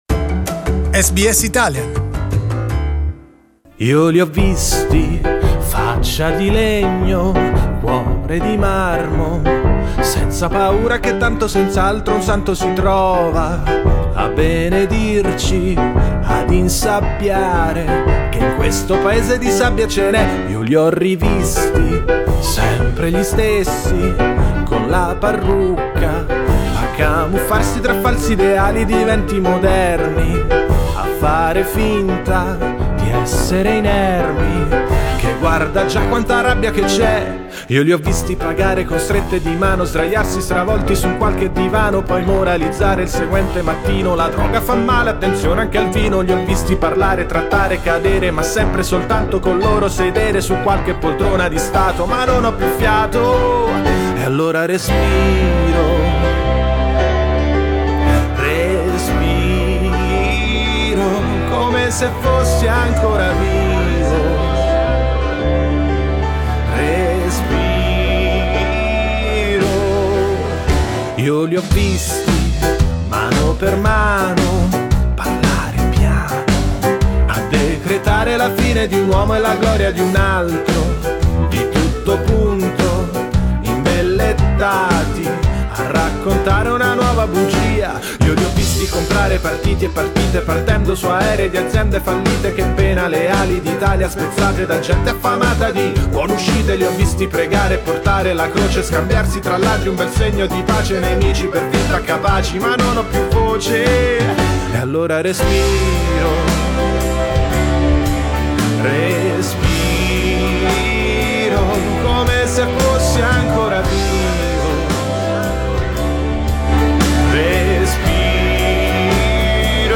He was a guest in our studio today. We talked about his participation in the event and his career and listened to two of his compositions.